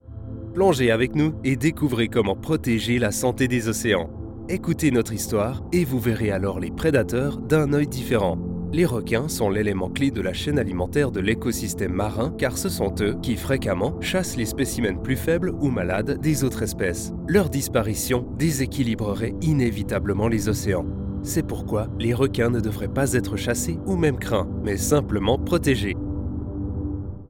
Male
Assured, Authoritative, Bright, Character, Confident, Cool, Engaging, Friendly, Natural, Reassuring, Smooth, Warm
Corporate.mp3
Microphone: Se X1
Audio equipment: Focusrite 2i2, Vocal booth